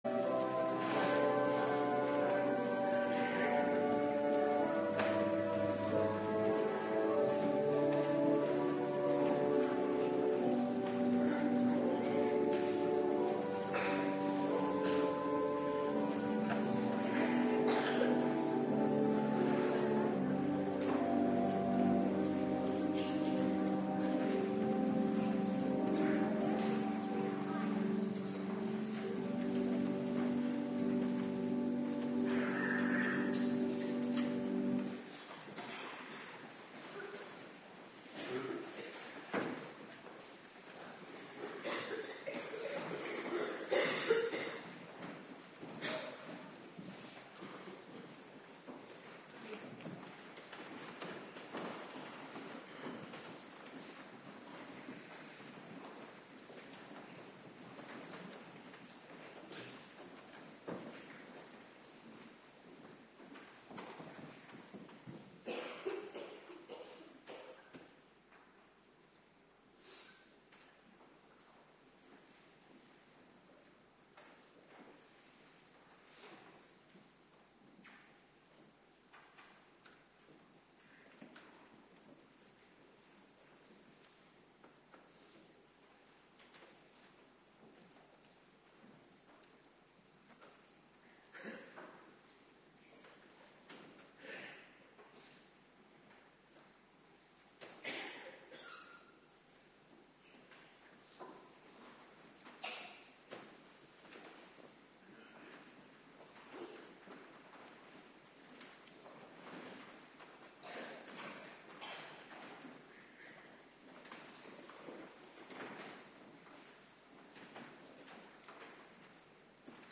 Morgendienst Heilig Avondmaal
09:30 t/m 11:00 Locatie: Hervormde Gemeente Waarder Agenda